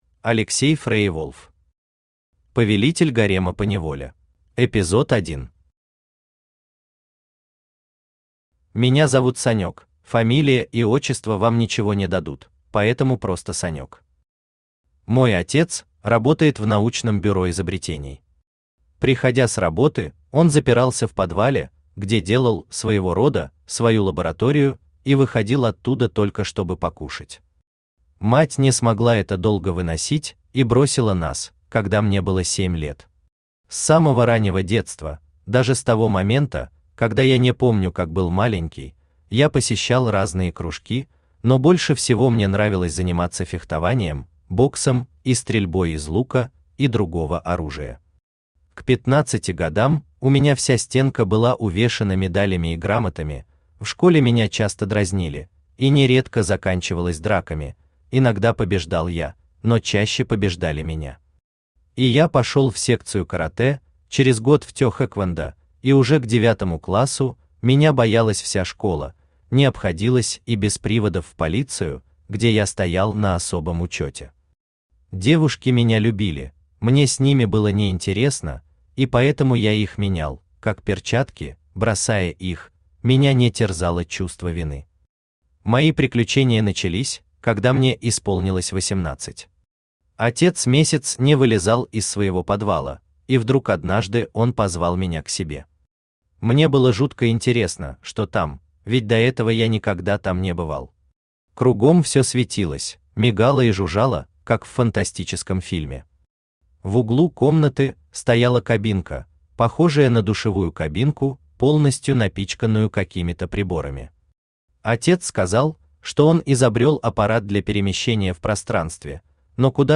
Аудиокнига Повелитель гарема поневоле | Библиотека аудиокниг
Aудиокнига Повелитель гарема поневоле Автор Алексей Леонидович FreierWolf Читает аудиокнигу Авточтец ЛитРес.